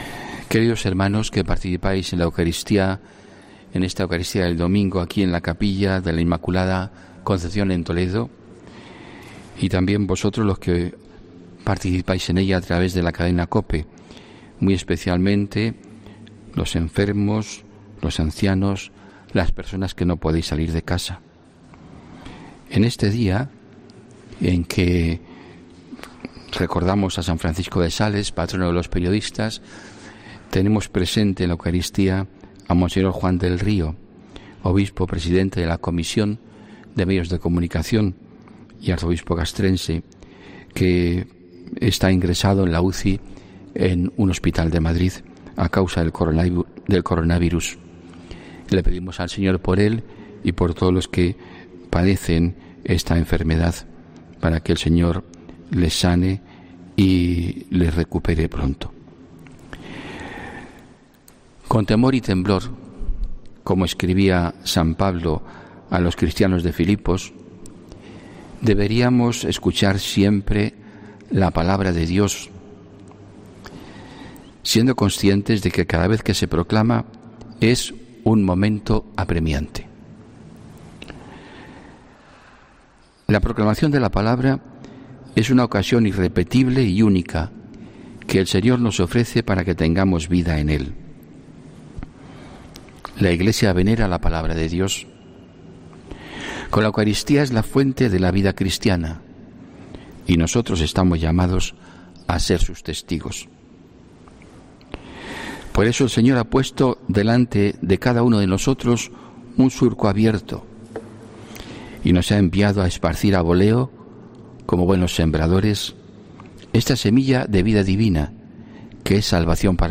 HOMILÍA 24 ENERO 2021